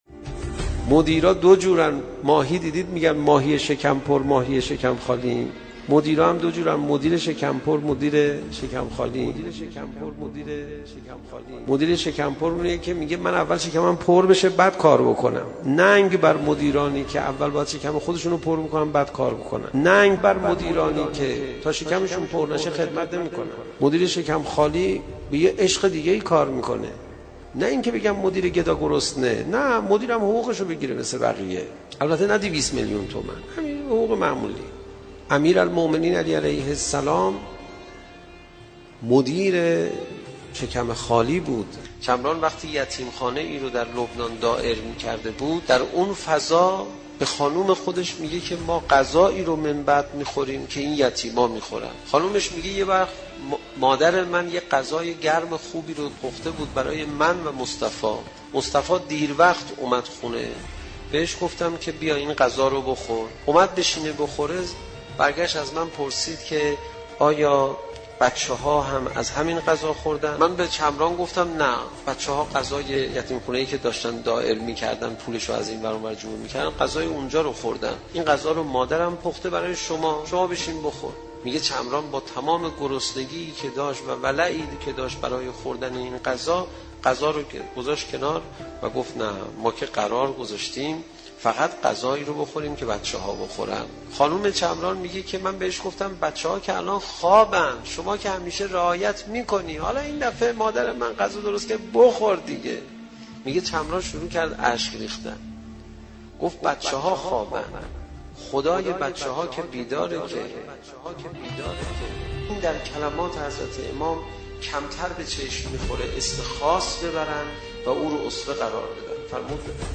صوت سخنرانی حجت الاسلام و المسلمین پناهیان درباره مدیران بی کفایت منتشر می شود.